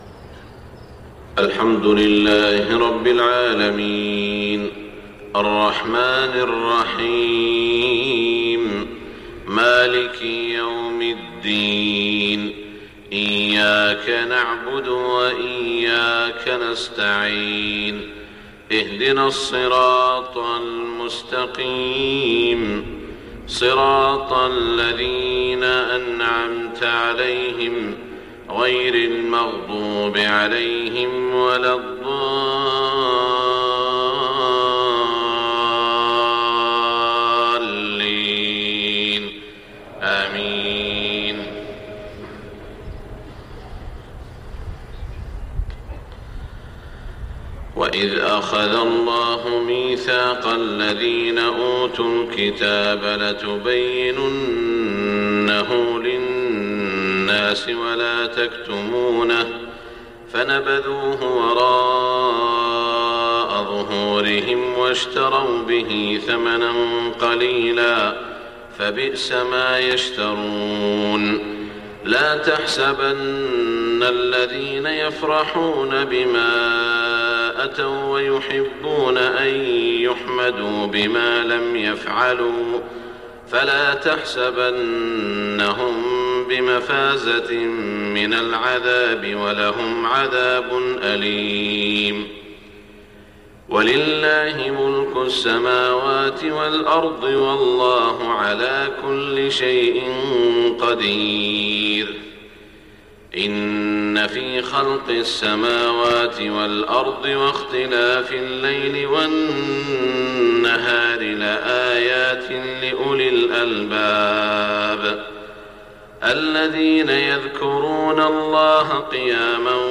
صلاة الفجر 6-8-1427 من سورة ال عمران > 1427 🕋 > الفروض - تلاوات الحرمين